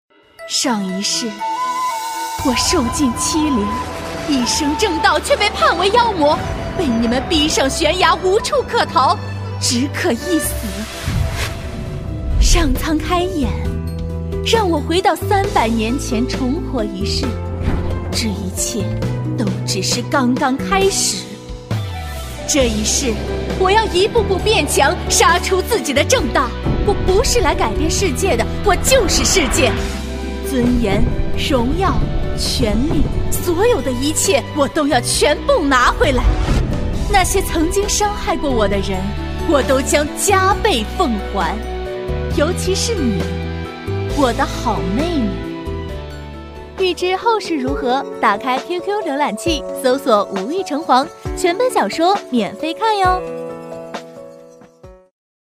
女C3-【QQ广告】网页小说
女C3-百变女王 夸张震撼
女C3-【QQ广告】网页小说.mp3